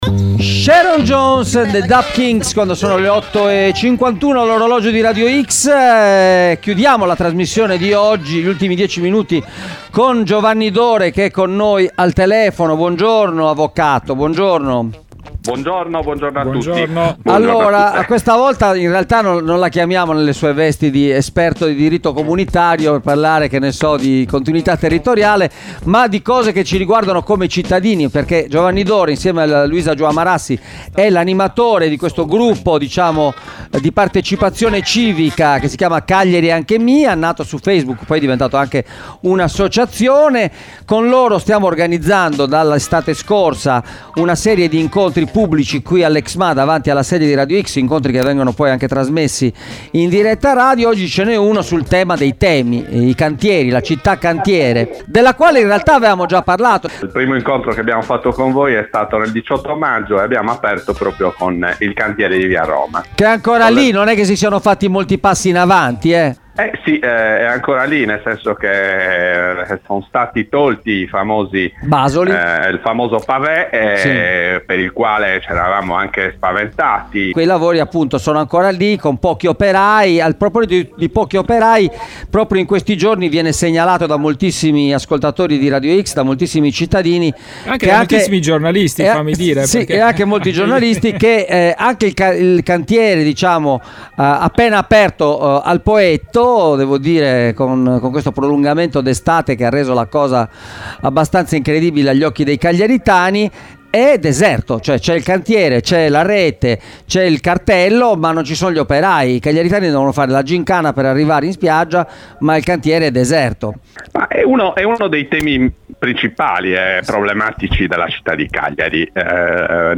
Cagliari città cantiere: all’Exma un incontro pubblico tra cittadini e amministratori – Intervista